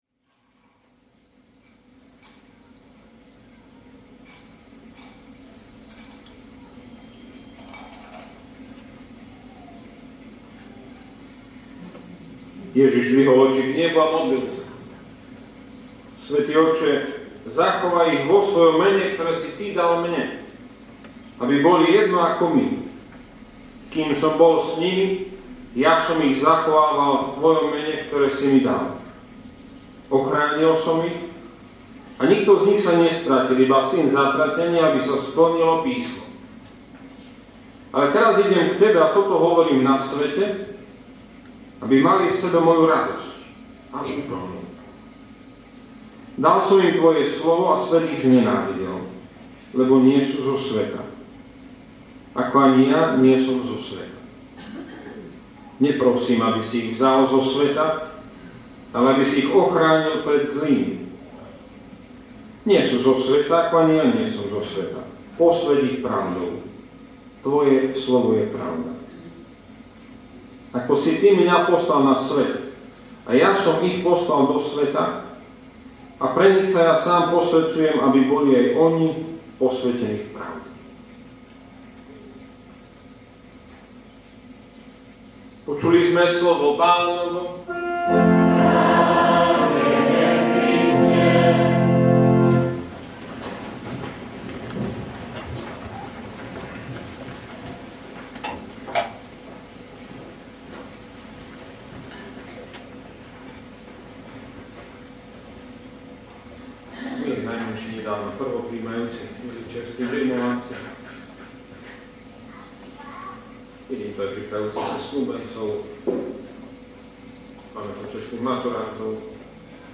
Odpustová kázeň - Ždaňa
Tu si môžete vypočuť odpustovú kázeň zo Ždane 24.5.2009.